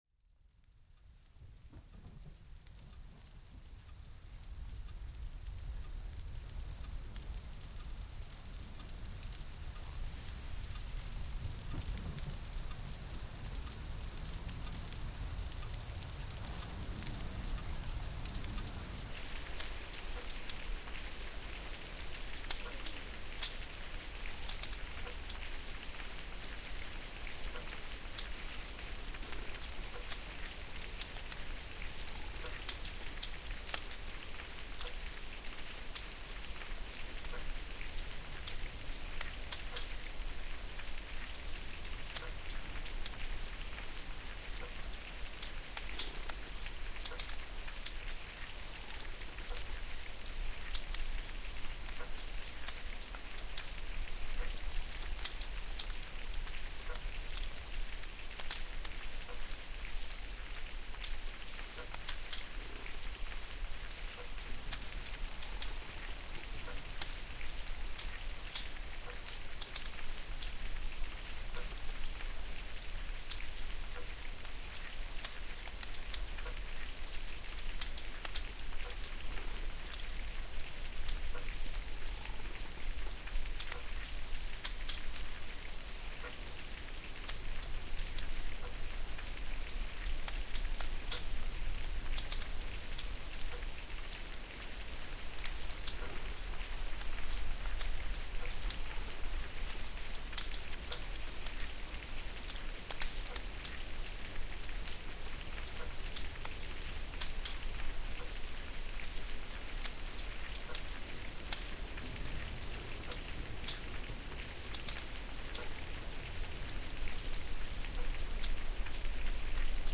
Over 1 Hour of Stone FIREPLACE sounds in Large Rustic Cabin .... Wood Burning, Crackles, Pops, etc.